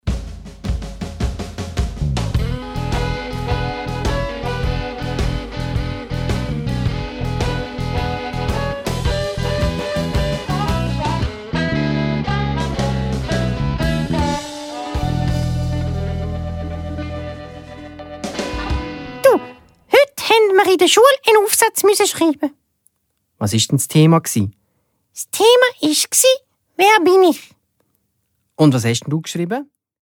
Laufzeit: 69 Minuten, Schweizerdeutsch